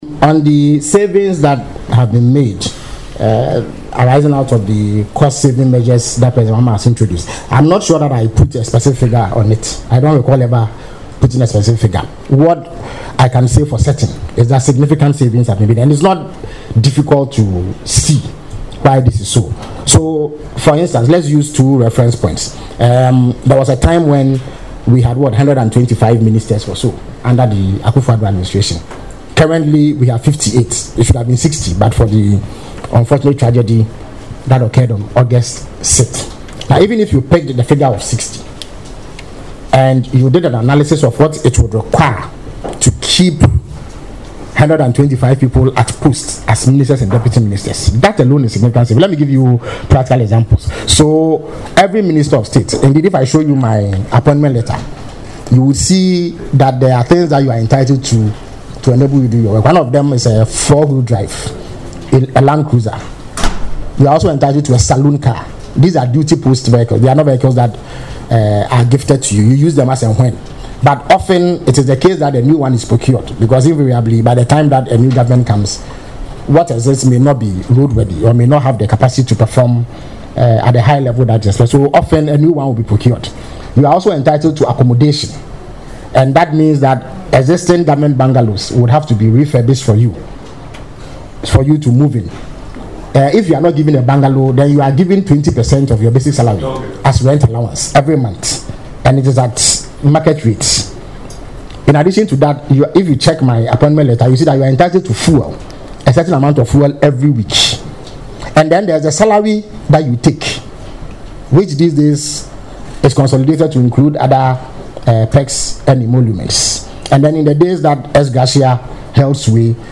Speaking during the Government Accountability Series on Wednesday, January 14, Mr. Kwakye Ofosu said it is beyond dispute that the current administration has made considerable savings, stressing that these savings must be acknowledged and officially recorded as part of transparent governance.
Listen to Felix Kwakye Ofosu explain how the savings were made in the audio below:👇